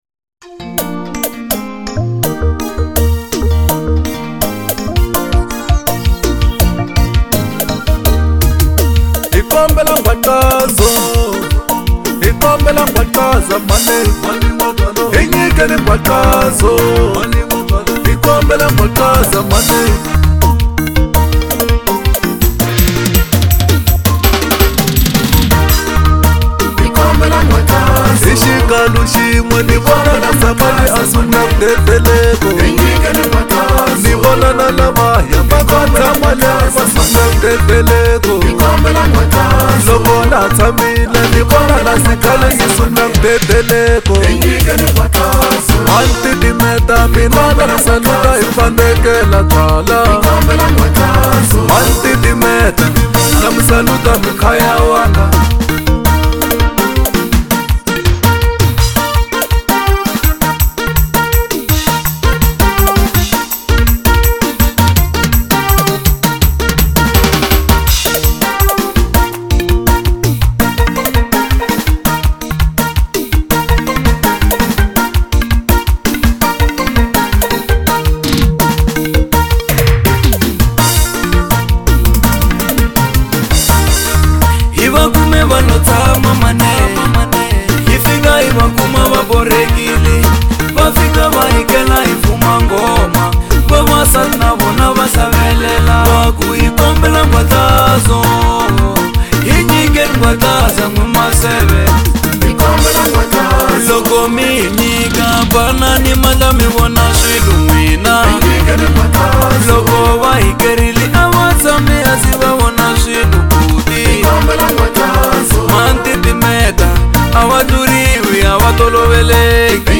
04:15 Genre : Xitsonga Size